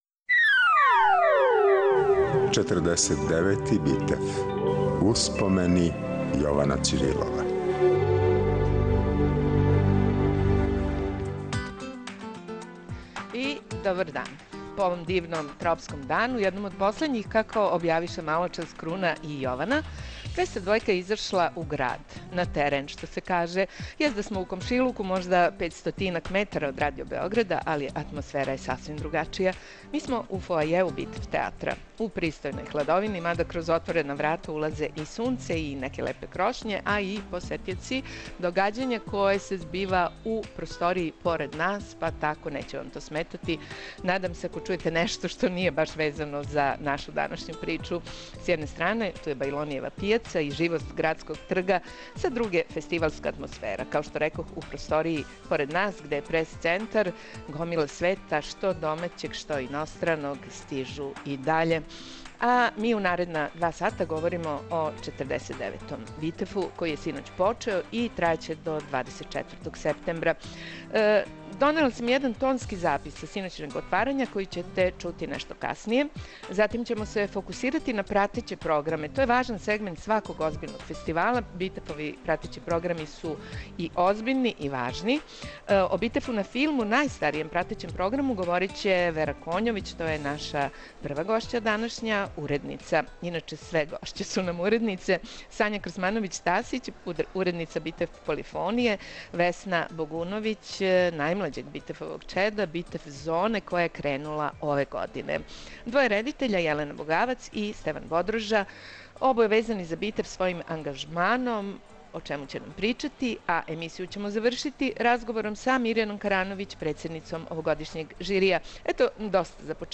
Дан после свечаног почетка 49. Битефа у београдском Народном позоришту, ''Визија 202'' ће се емитовати уживо из Битеф театра.